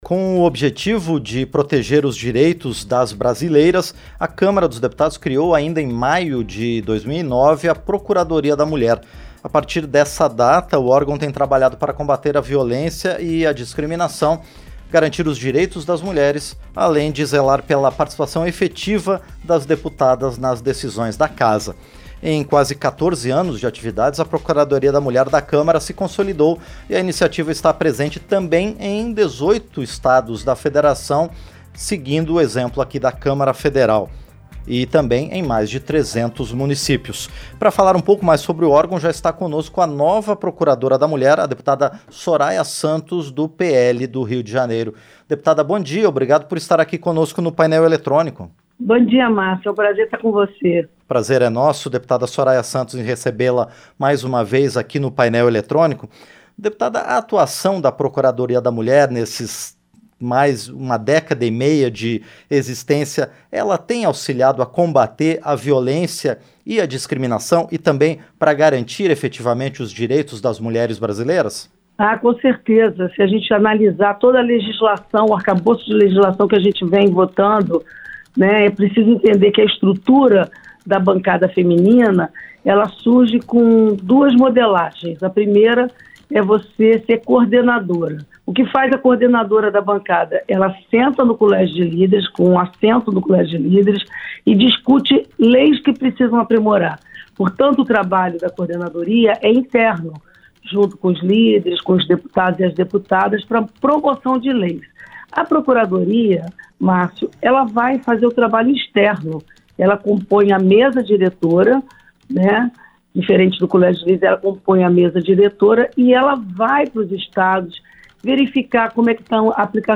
Entrevista - Dep. Soraya Santos (PL-RJ)